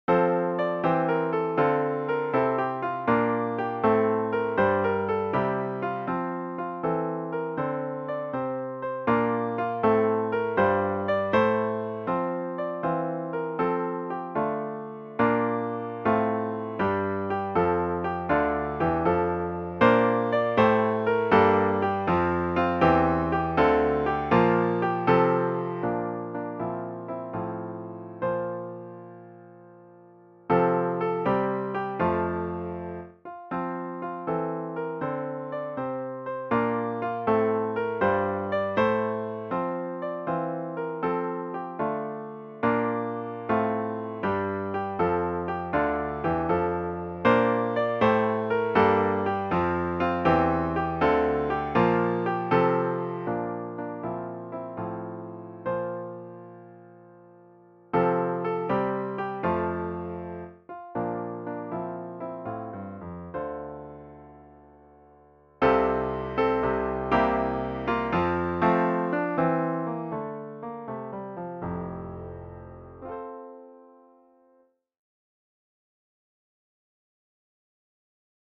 Christmas Piano Book – intermediate to late intermediate